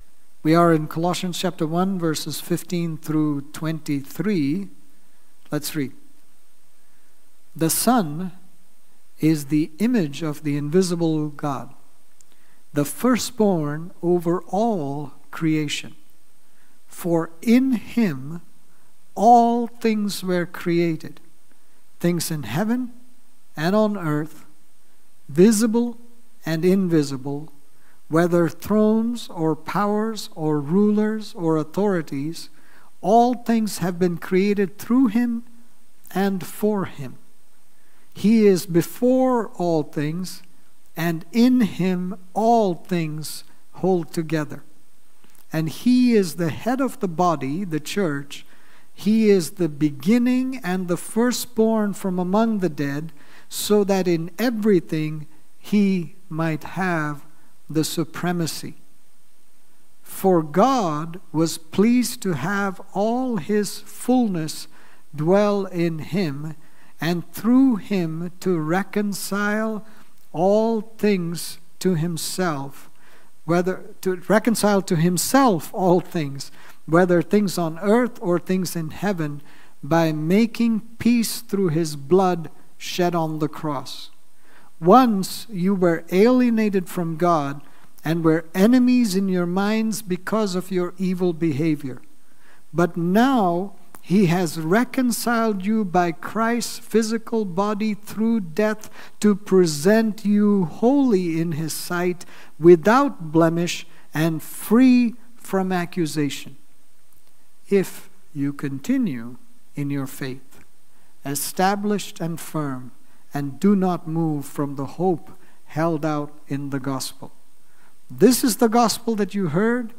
Sermons | New Life Fellowship Church